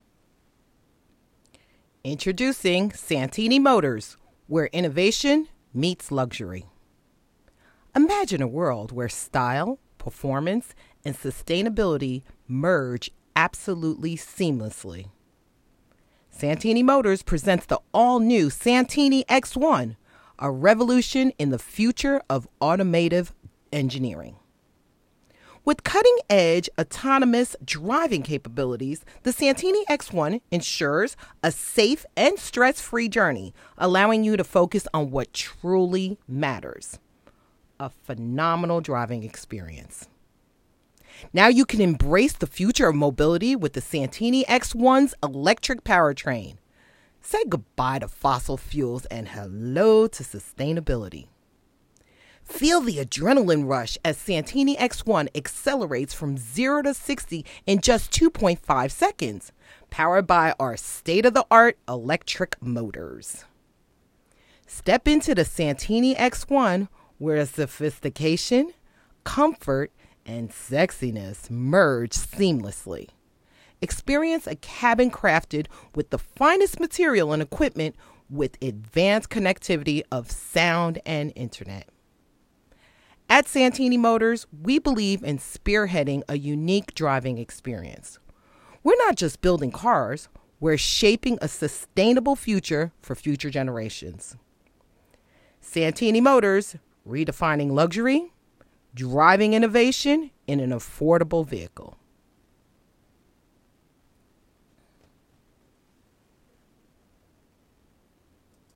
Vehicle Commercial
Vehicle-Motor-Commercial.m4a